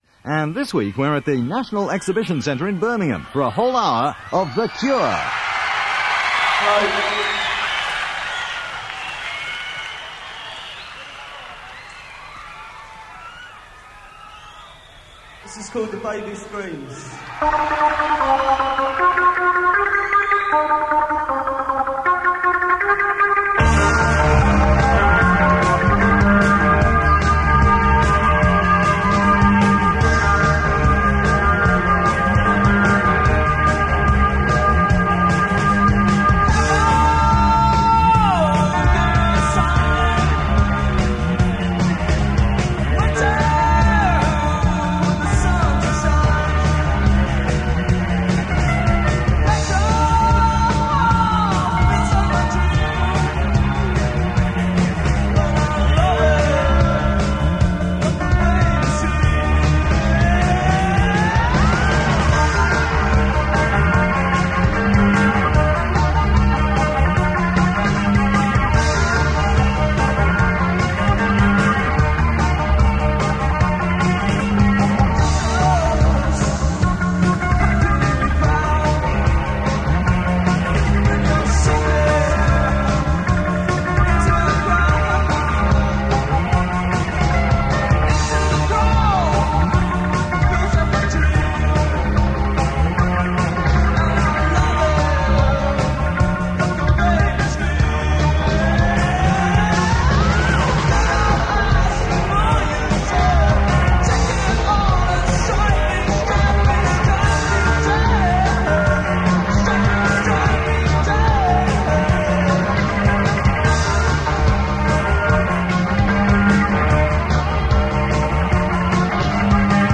drummer